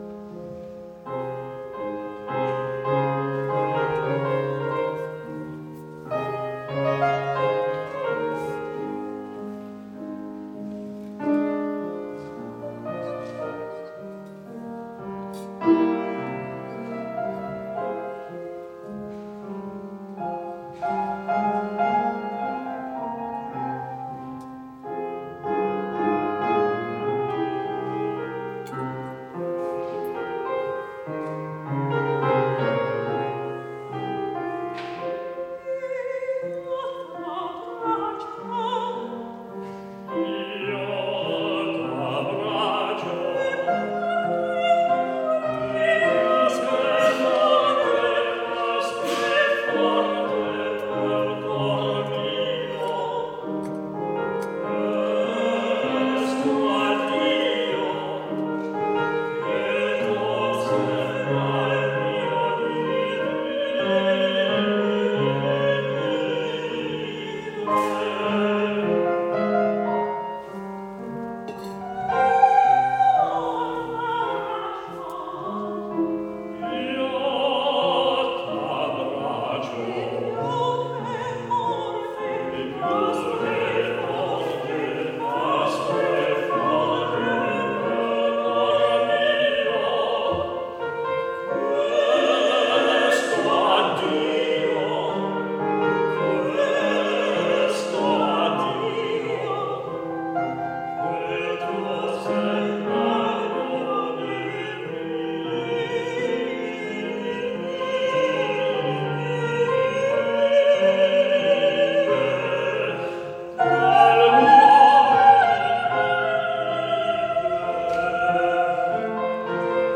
DUETS:
soprano
tenor
piano - live in concert 2023